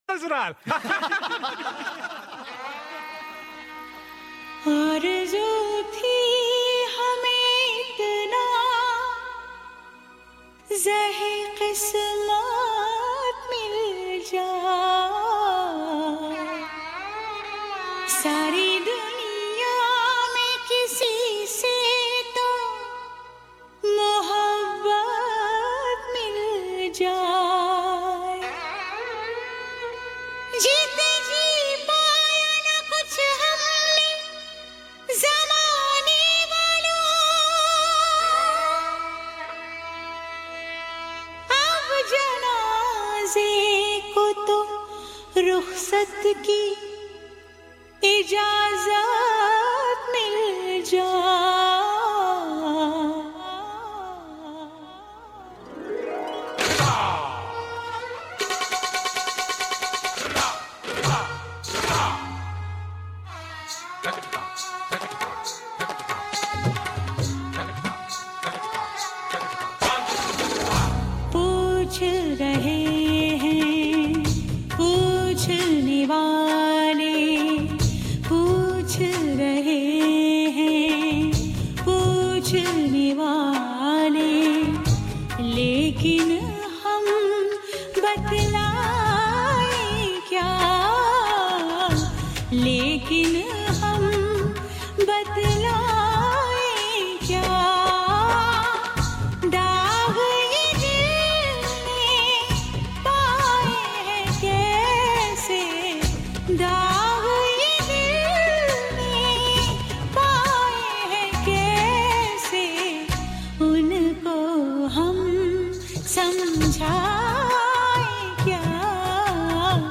Carpeta: musica hindu mp3